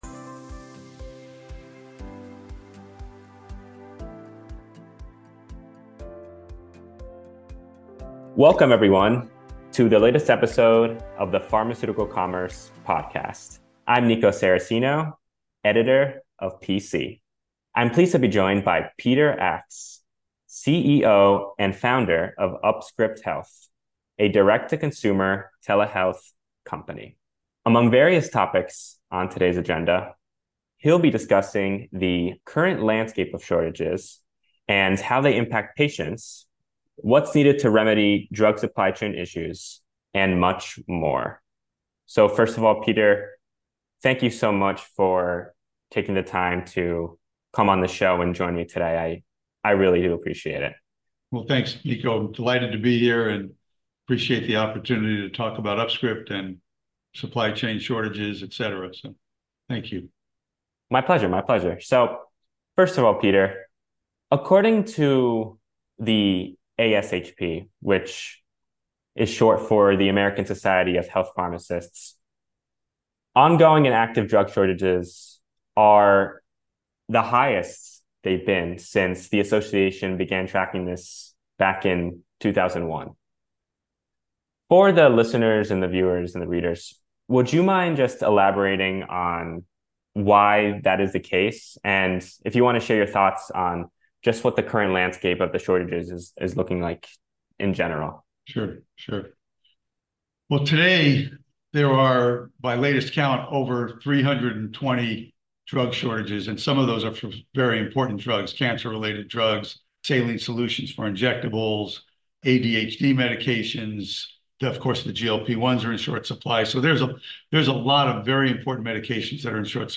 In this exclusive PC Podcast interview